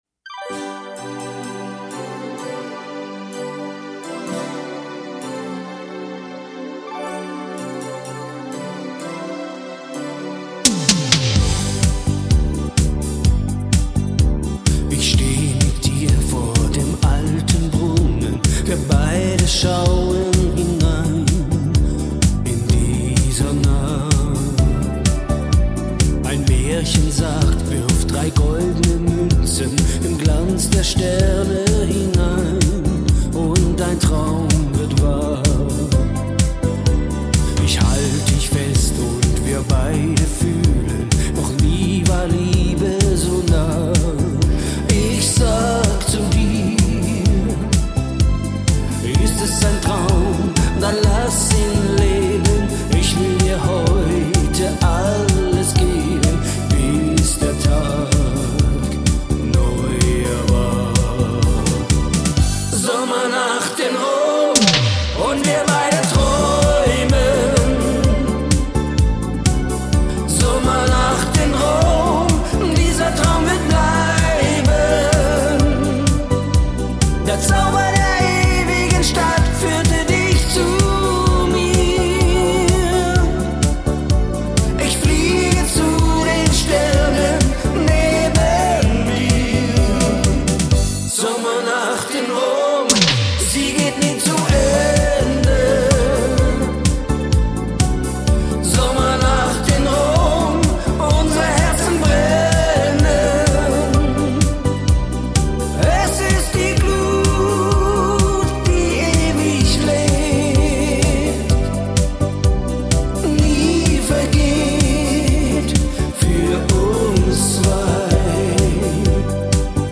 Partyband Hochzeit Fasnet Singen Hegau Bodensee
• Allround Partyband